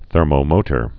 (thûrmō-mōtər)